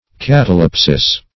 Search Result for " catalepsis" : The Collaborative International Dictionary of English v.0.48: Catalepsy \Cat"a*lep`sy\, Catalepsis \Cat`a*lep"sis\, n. [NL. catalepsis, fr. Gr.
catalepsis.mp3